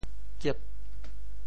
“浹”字用潮州话怎么说？
kiep8.mp3